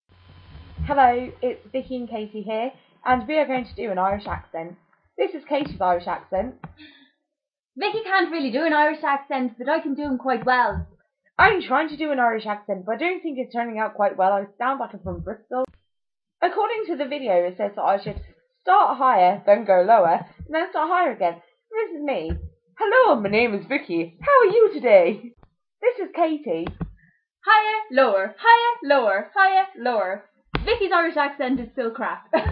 how to do an Irish accent!!!